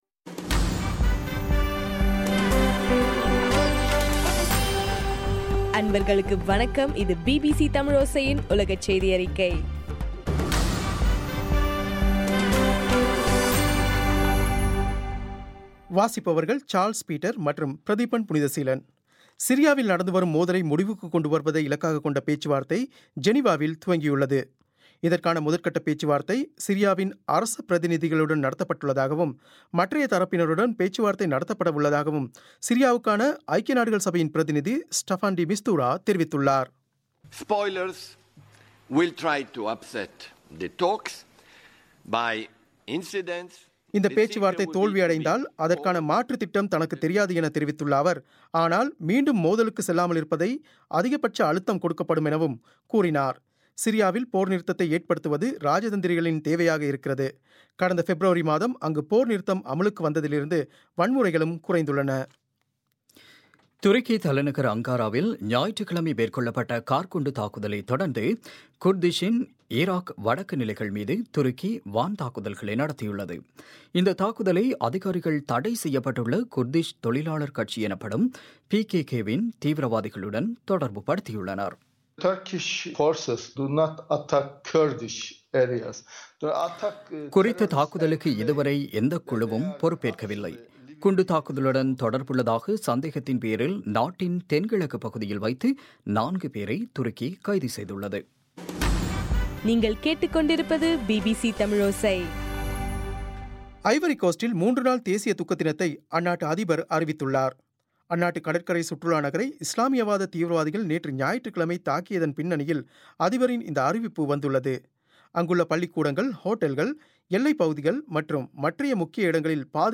மார்ச் 14, 2016 பிபிசி தமிழோசையின் உலகச் செய்திகள்